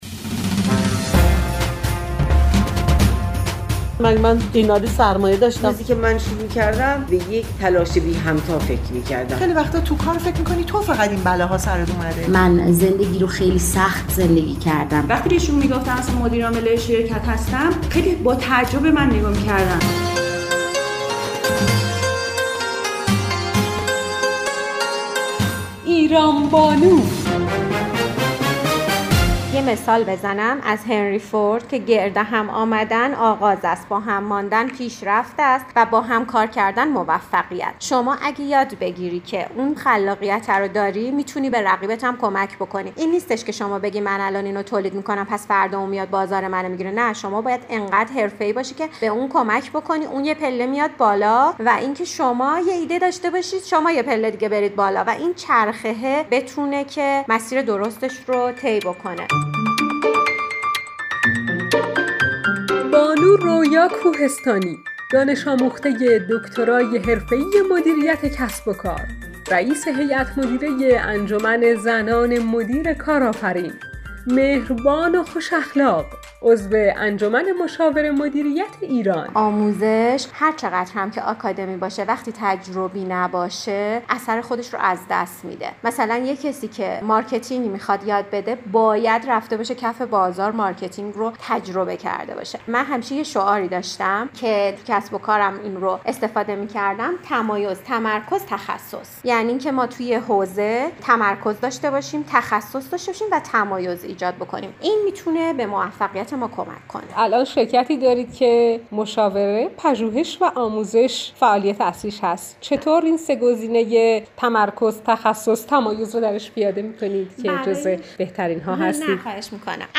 برنامه رادیویی ایران بانو گفتگوی رادیو اقتصاد - 1399